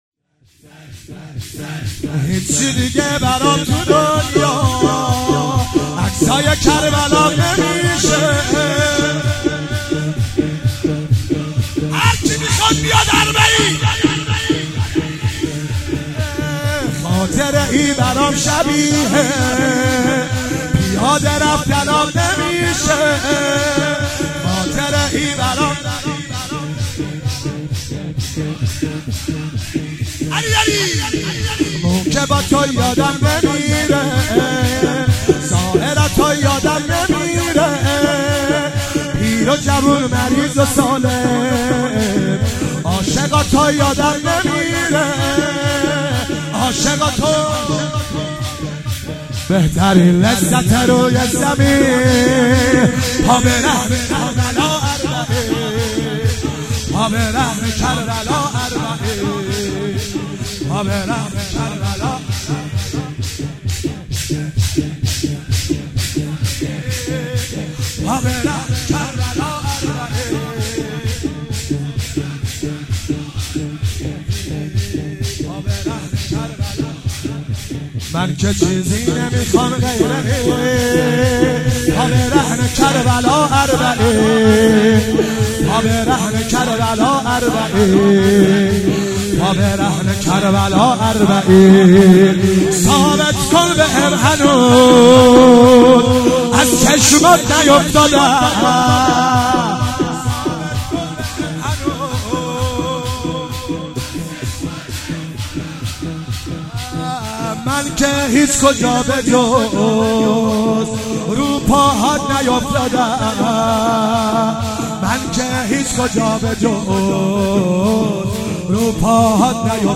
شب هفتم محرم 96 - شور -هیچی دیگه برام تو دنیا